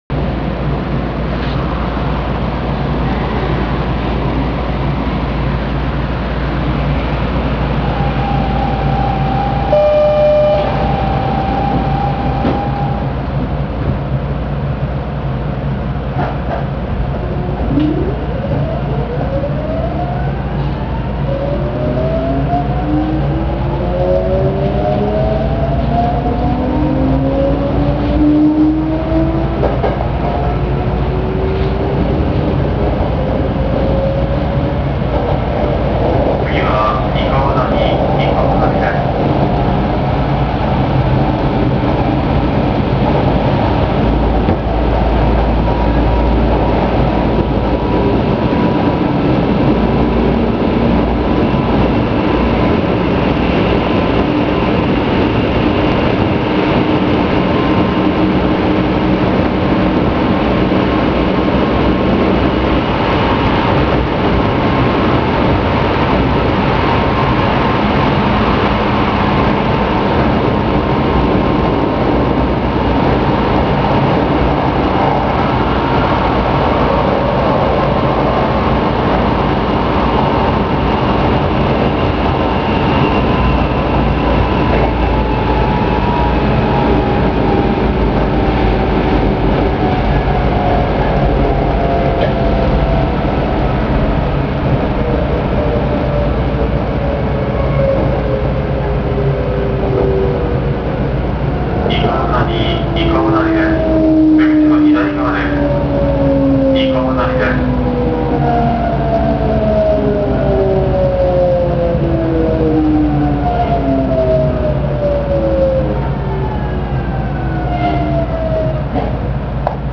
・7000系走行音
【西神・山手線】学園都市〜伊川谷（2分7秒：694KB）
初期の三菱GTOで、これも嘗てはそれほど珍しい走行音でも無かったのですが、現在では類似の音を出す車両も殆ど無くなってしまいました。現在は三菱SiCに更新されているので、この音も聞けなくなってしまっています。